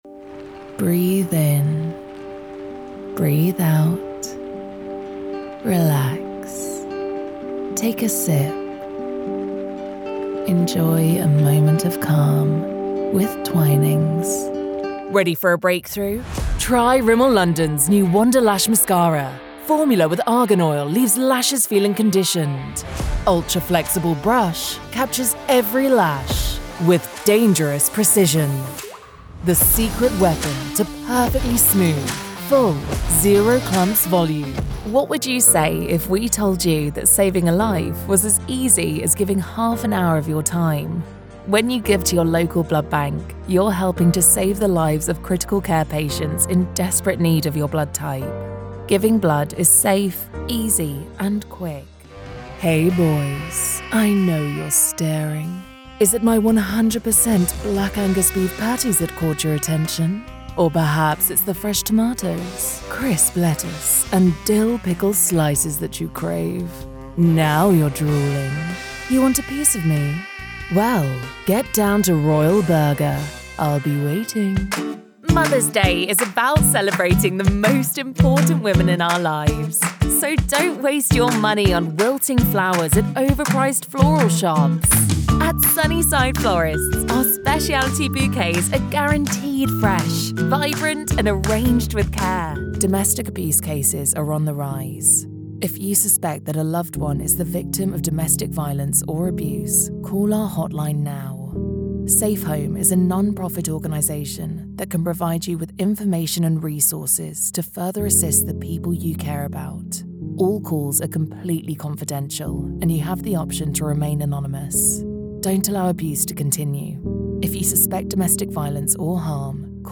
Female TEENS , 20s British English (Native) Bright , Bubbly , Character , Children , Confident , Cool , Engaging , Friendly , Natural , Soft , Streetwise , Warm , Versatile , Young